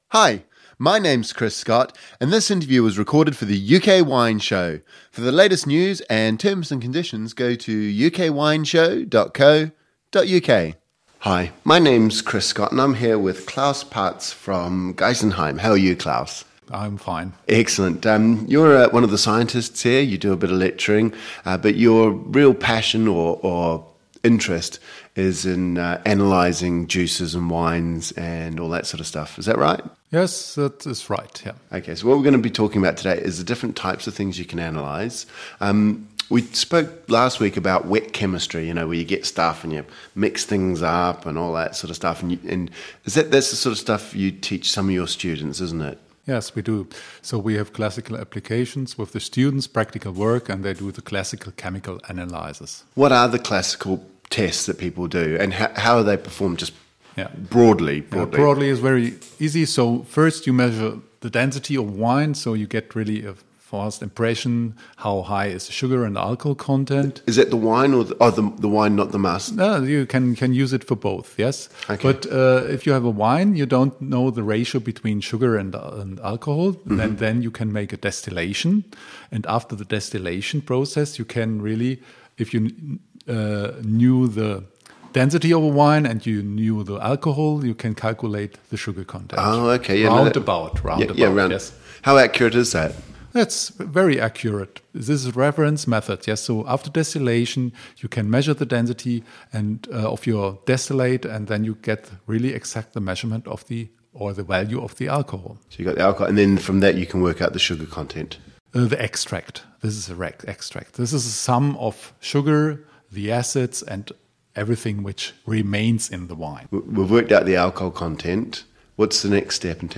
In this interview he explains how wines are analysed to measure the density of wine, the sugar, acidity, acids and alcohol, among others. The wines are measured throughout the process, pre and post fermentation, and sometimes these measurements are used for making declarations on German wine labels. We hear about the various techniques and tests used in the analysis.